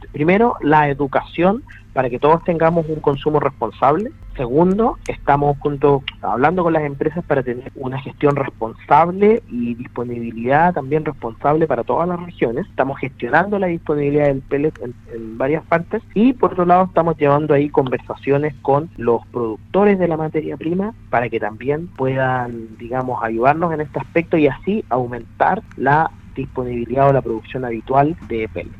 En conversación con Radio Sago el Subsecretario de Energía, Julio Maturana, expuso cómo ha evolucionado la producción de pellet, teniendo en cuenta que durante las últimas dos semanas se ha visto una escasez de este producto.